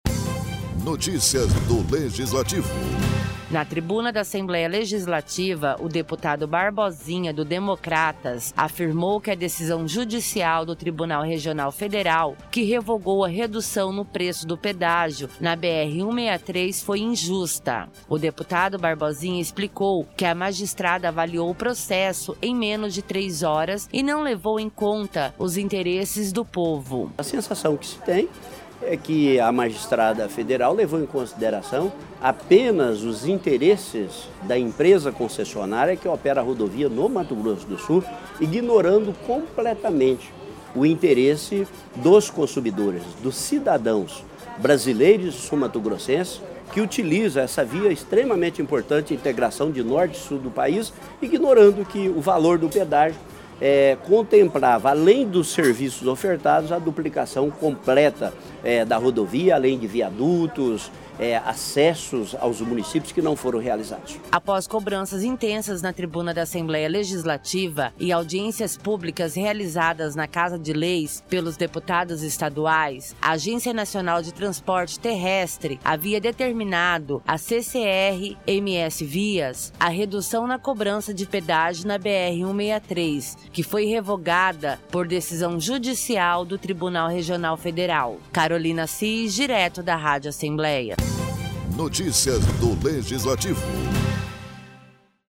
Poucos dias após comemorar determinação da Agência Nacional de Transportes Terrestres (ANTT) para a redução média de 53,94% no preço do pedágio na BR-163, o deputado Barbosinha, do Democratas retornou à tribuna da Assembleia Legislativa para lamentar a decisão judicial que revogou o desconto.